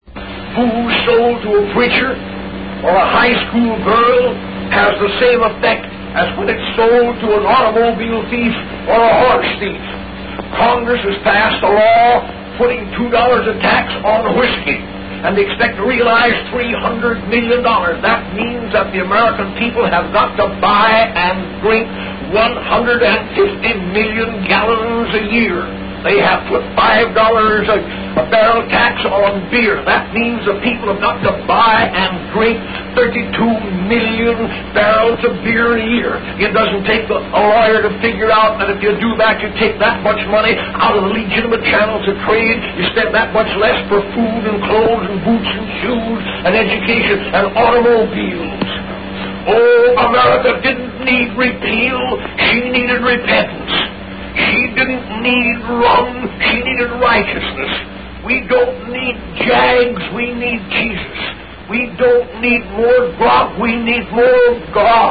In this sermon, the preacher discusses the negative effects of alcohol consumption on society.